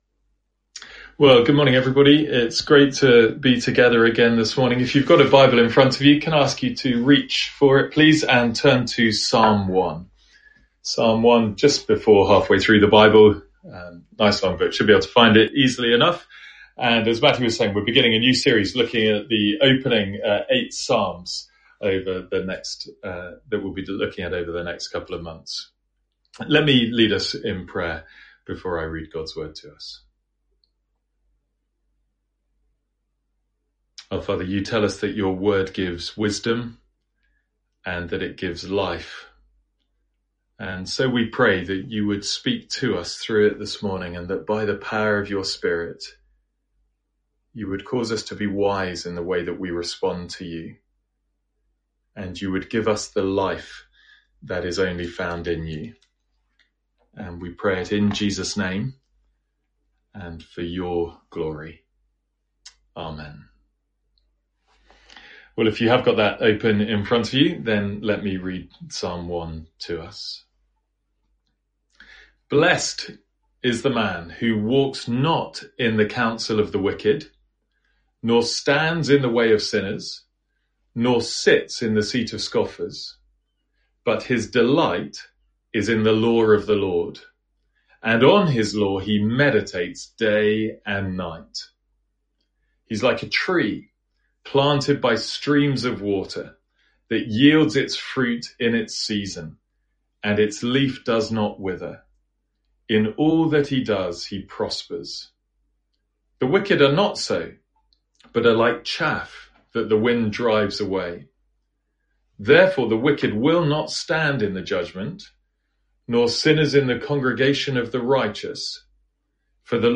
From our morning service in the Psalms.